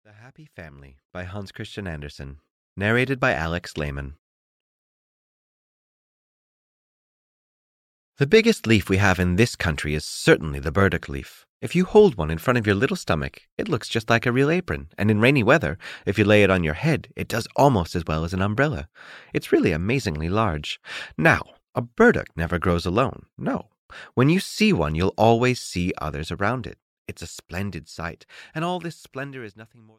The Happy Family (EN) audiokniha
Ukázka z knihy